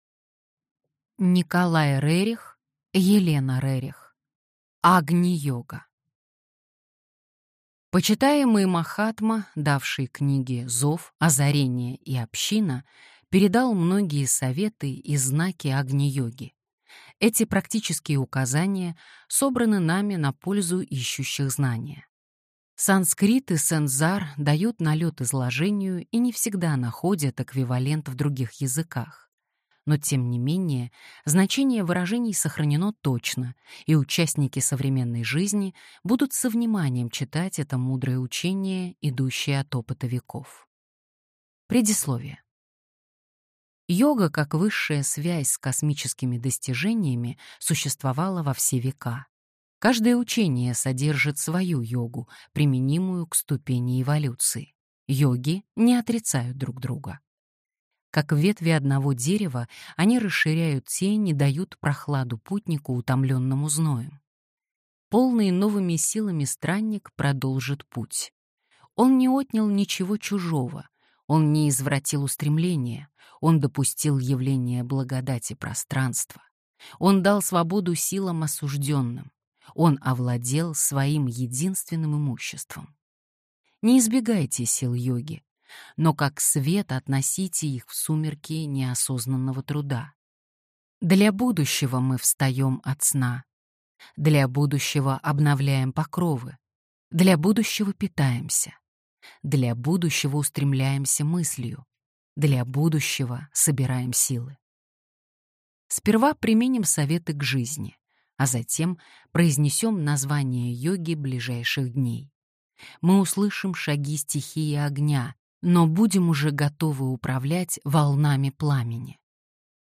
Аудиокнига Агни-йога | Библиотека аудиокниг